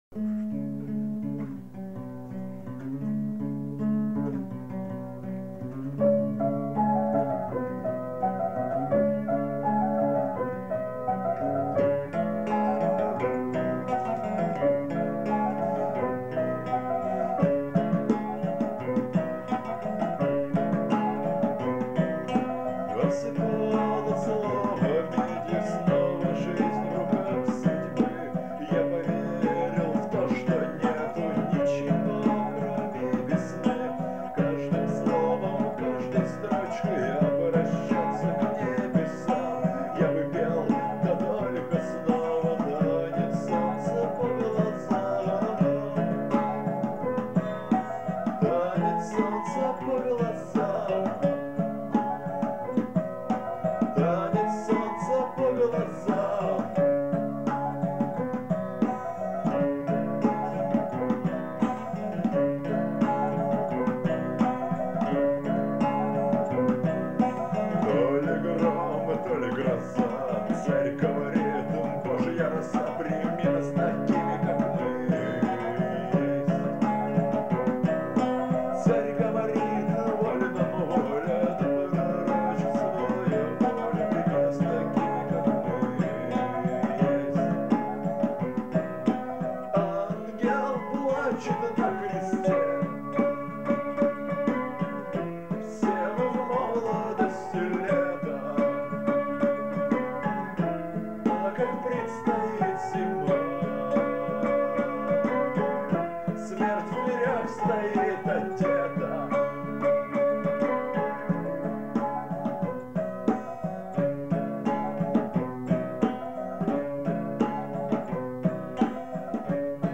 Акустика
вокал, гитара
перкуссия
альт